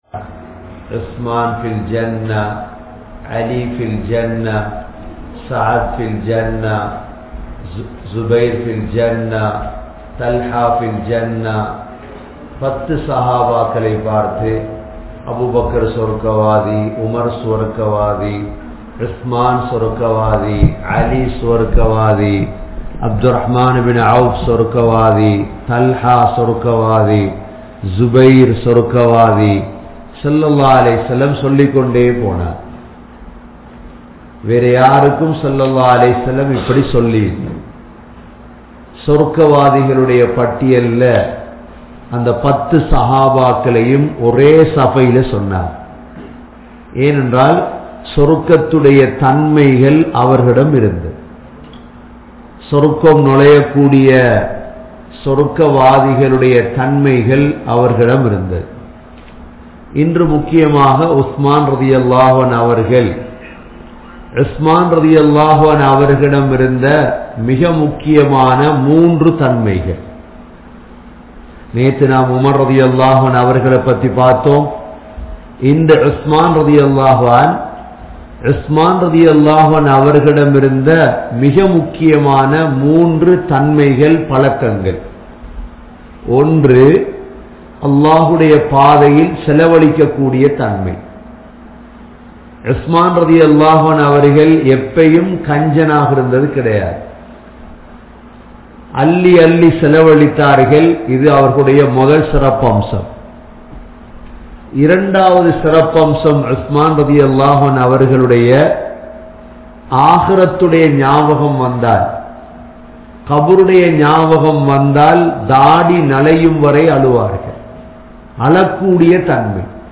Usman(Rali) | Audio Bayans | All Ceylon Muslim Youth Community | Addalaichenai